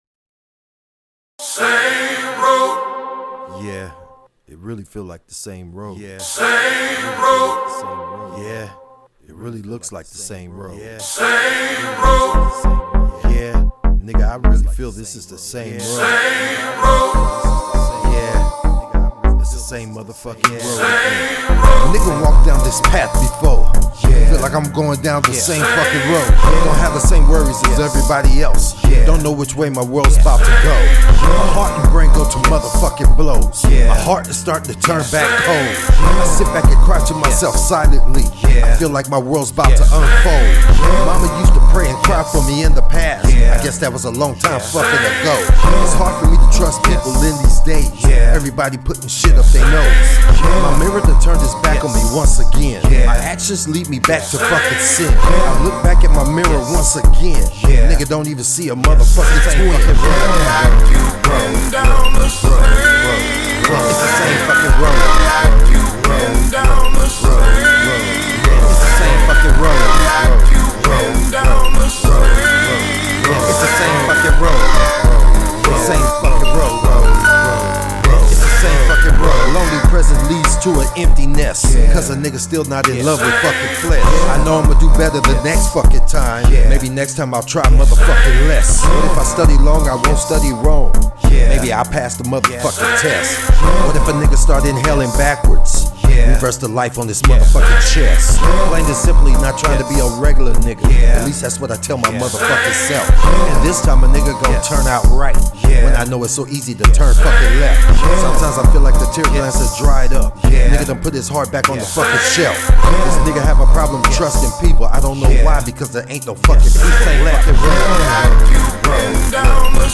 NICE BEAT